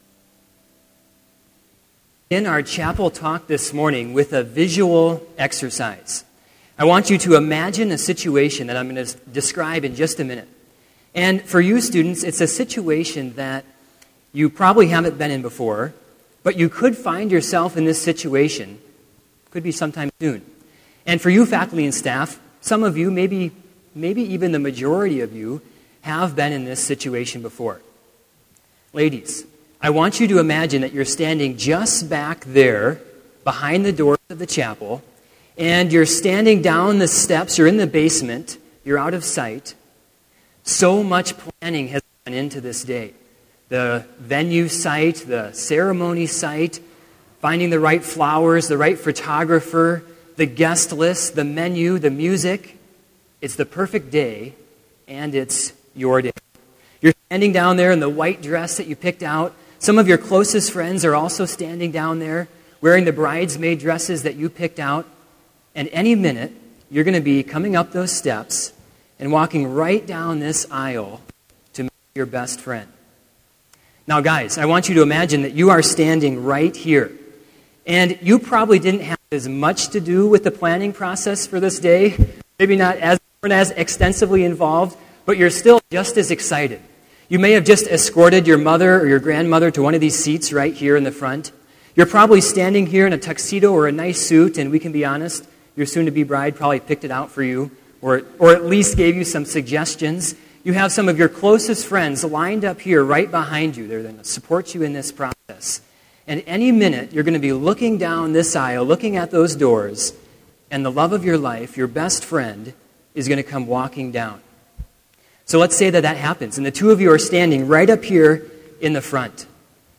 Sermon audio for Chapel - January 22, 2016